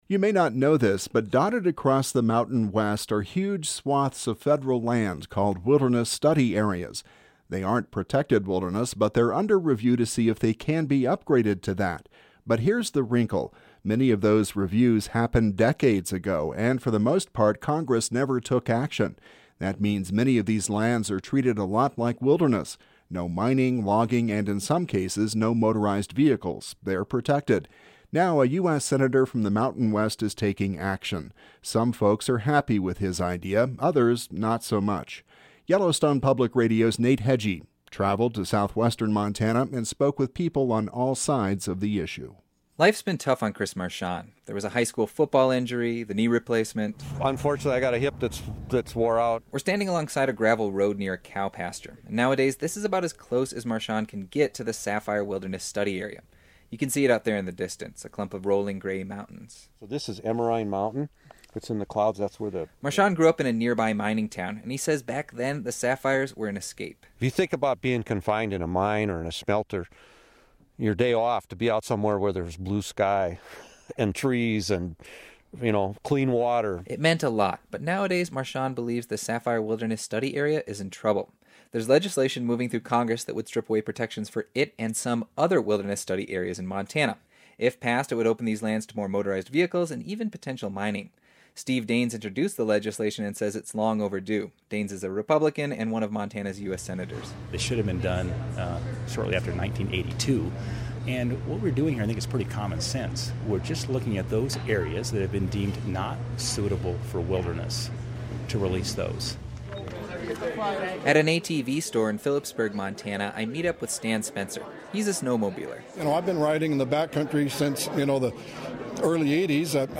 We’re standing alongside a gravel road near a cow pasture.